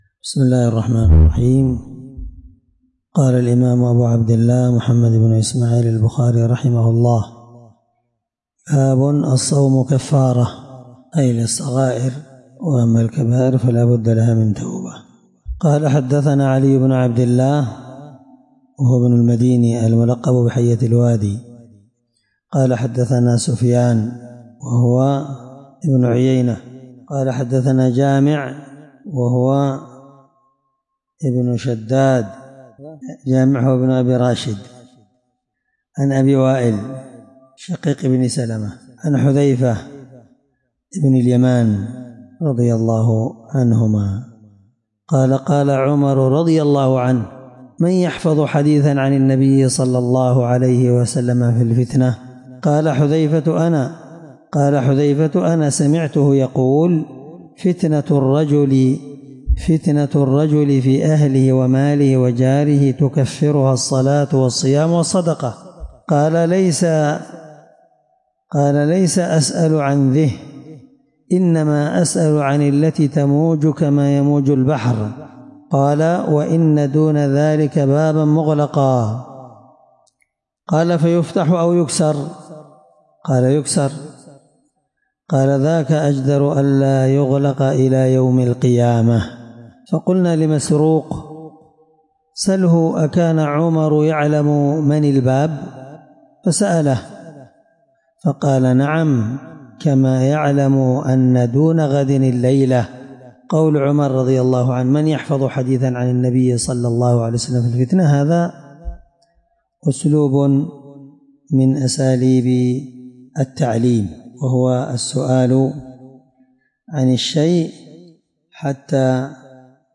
الدرس 3من شرح كتاب الصوم حديث رقم(1895 )من صحيح البخاري